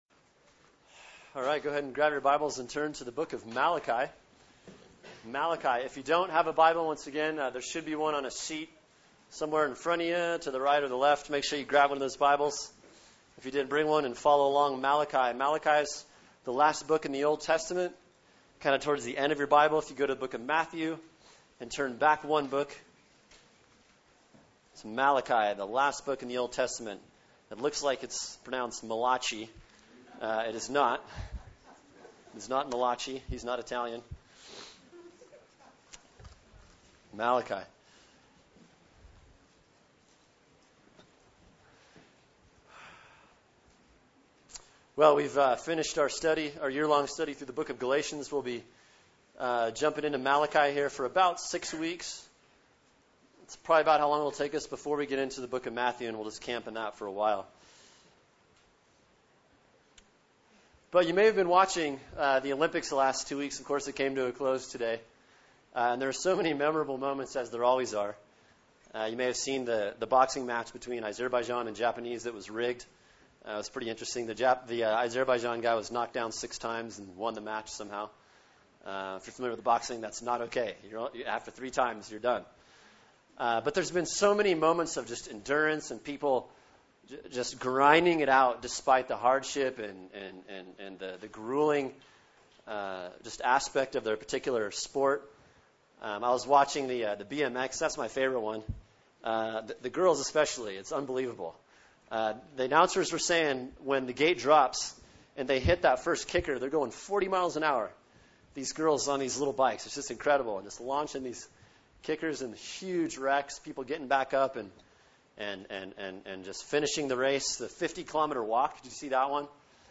[sermon] Malachi 1:1-5 “God’s Unchanging Love” | Cornerstone Church - Jackson Hole